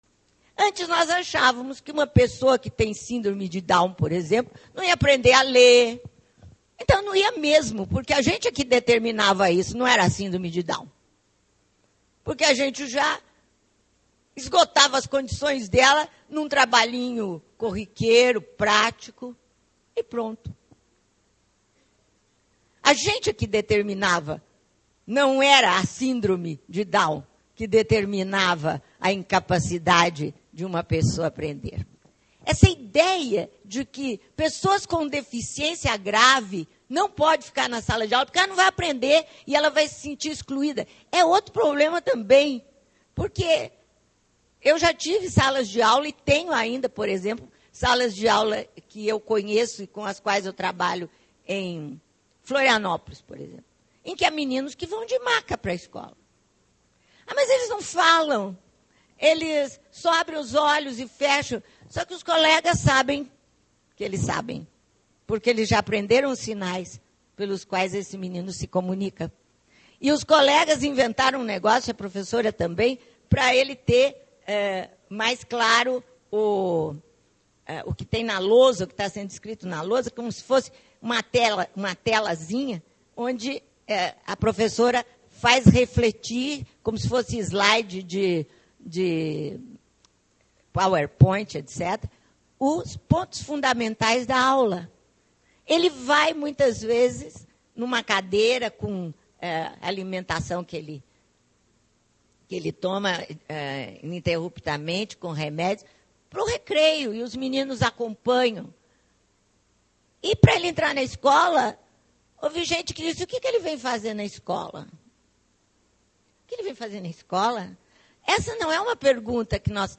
Durante o evento, que contou mais de 400 participantes, falou-se sobre a legislação em vigor, as experiências aplicadas, pesquisas realizadas, sobre os compromissos e responsabilidades da escola e do professor, sobre formação docente.